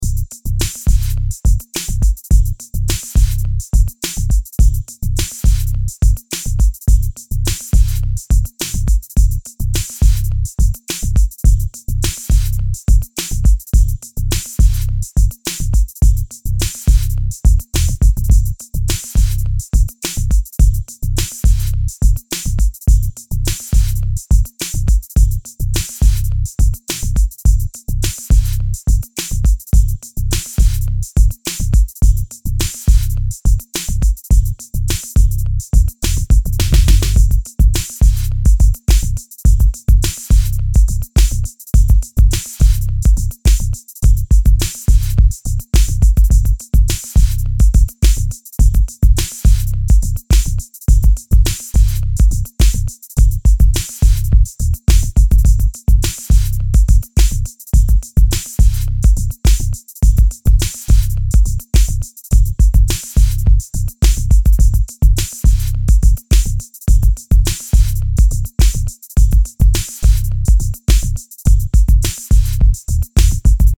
リズムトラックオンリーの素材です。